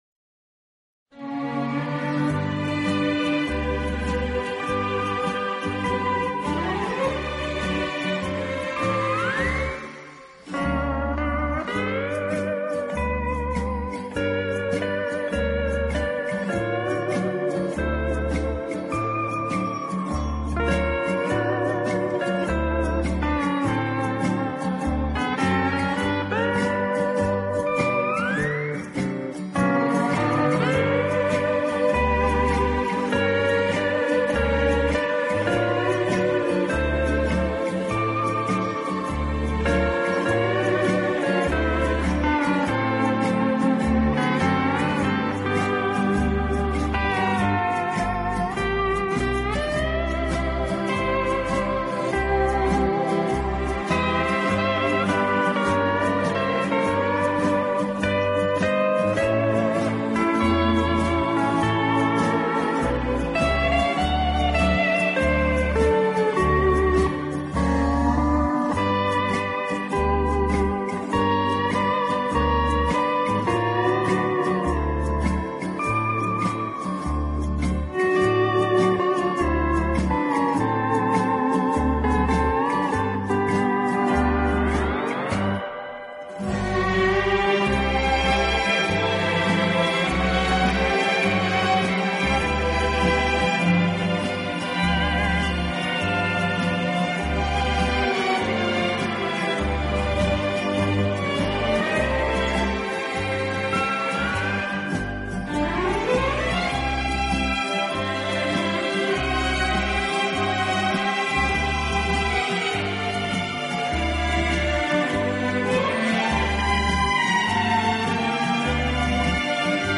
十二集超过二百首流行音乐元素与世界各地风情韵味完美结合的音乐，